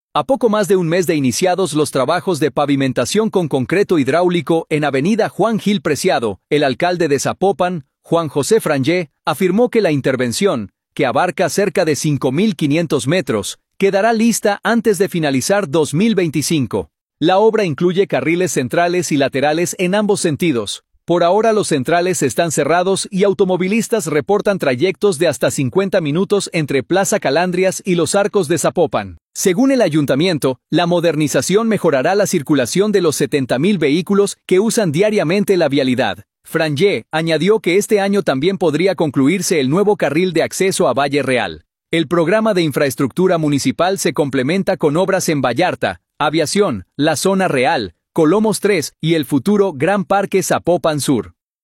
A poco más de un mes de iniciados los trabajos de pavimentación con concreto hidráulico en avenida Juan Gil Preciado, el alcalde de Zapopan, Juan José Frangie, afirmó que la intervención (que abarca cerca de 5 mil 500 metros) quedará lista antes de finalizar 2025.